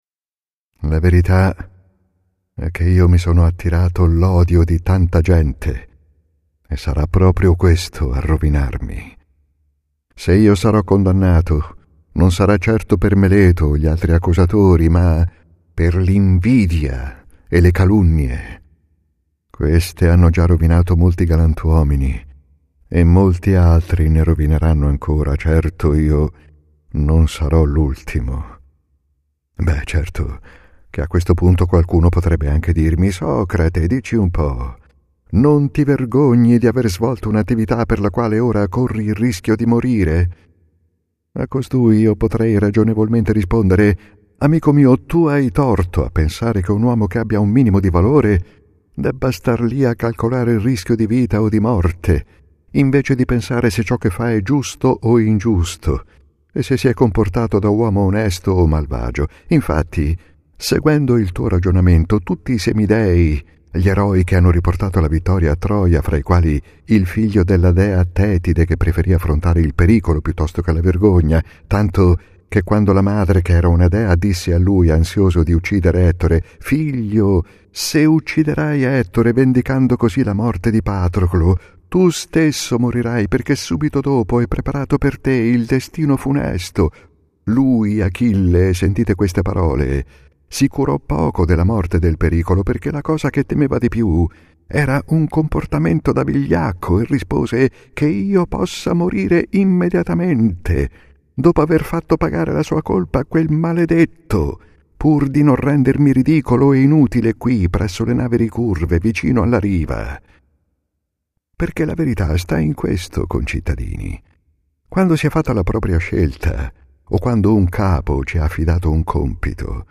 Durata: 1h - 01' - edizione integrale
©2019 audiolibro